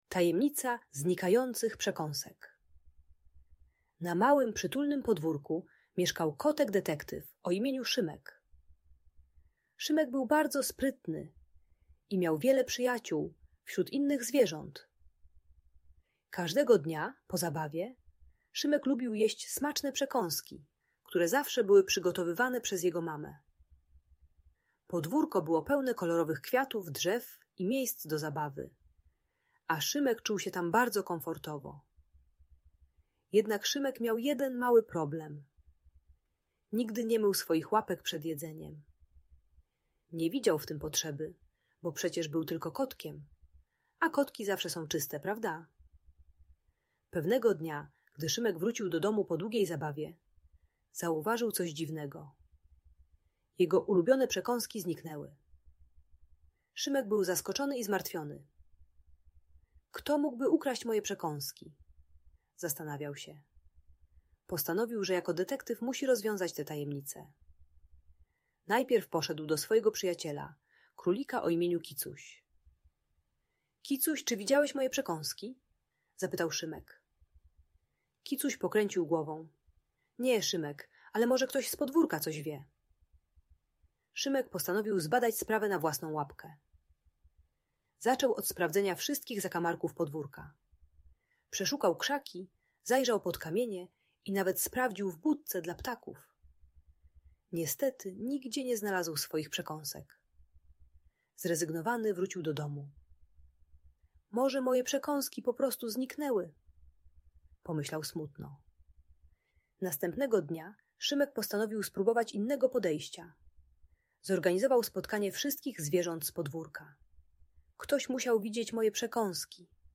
Tajemnicza historia znikających przekąsek - Audiobajka dla dzieci